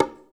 13 CONGA.wav